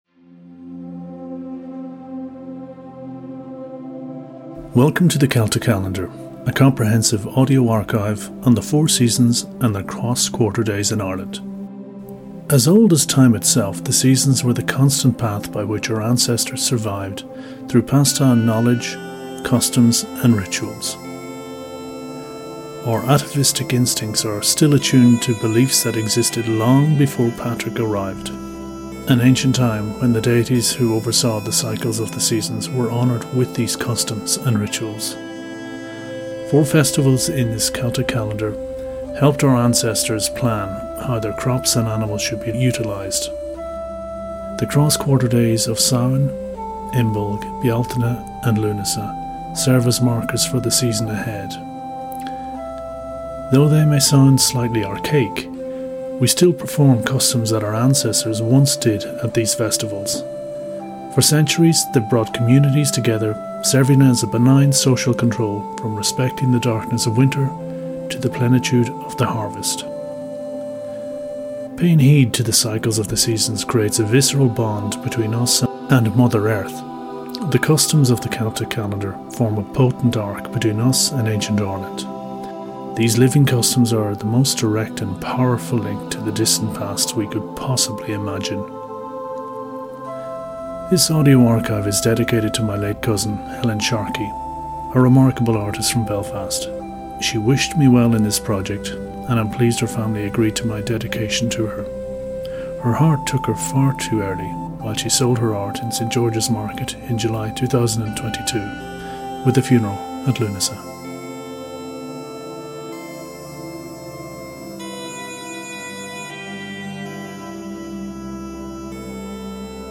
We've used a variety of styles from scripted audio to live and natural to outdoors and yes, Zoom - it adds a nice mix for what is in itself an often unpredictable miscellany!